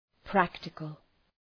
Προφορά
{‘præktıkəl}